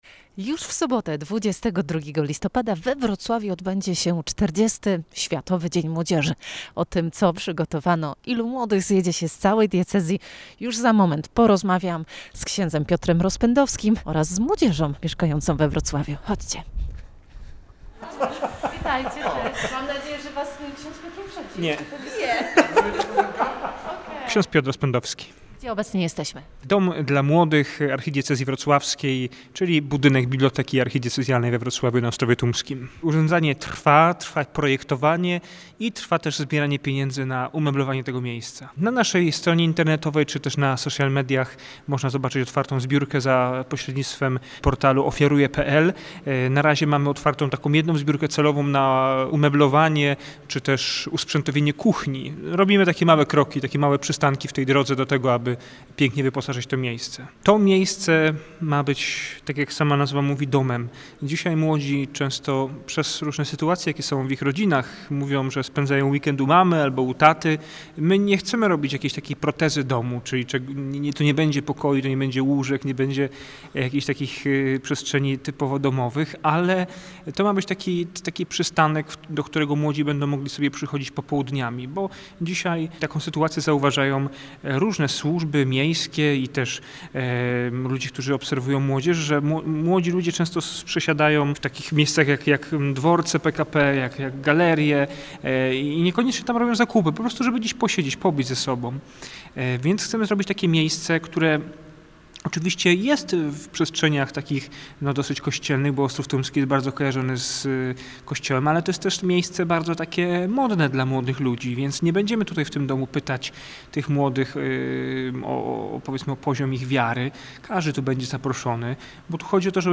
Emisja rozmowy w piątek po godz. 10:10.